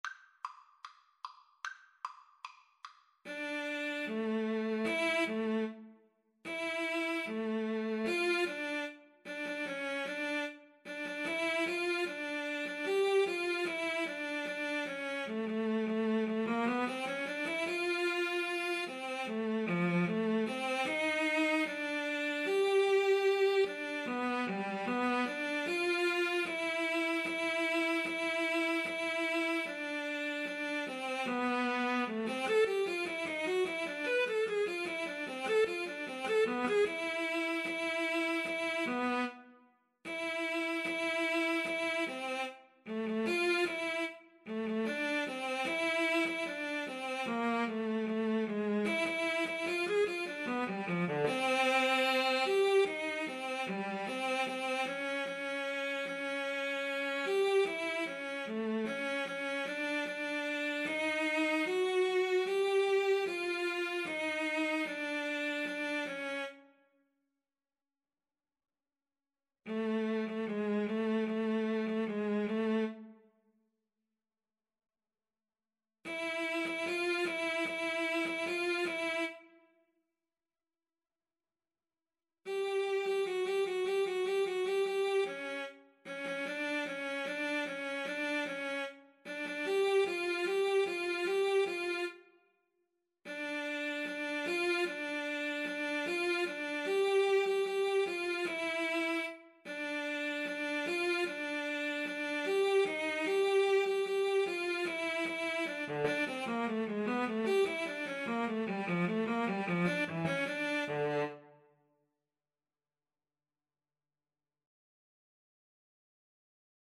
Classical (View more Classical Cello Duet Music)